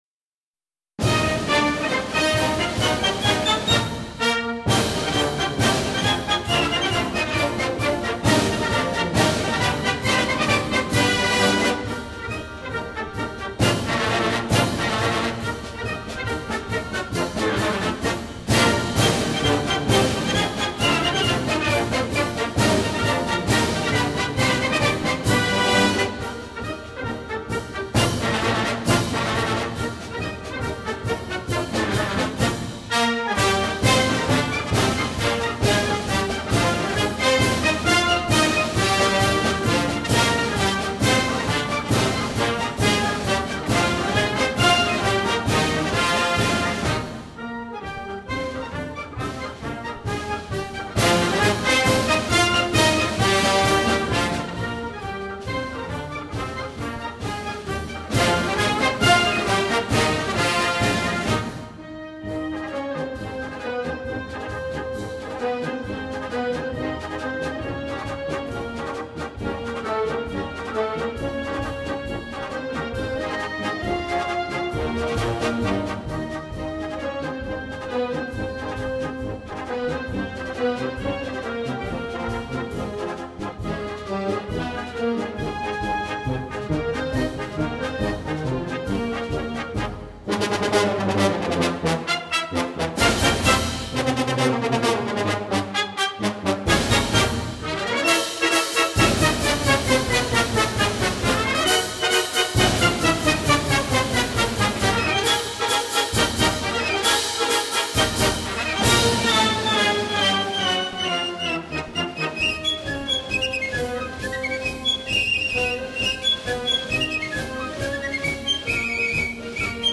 patriotic American march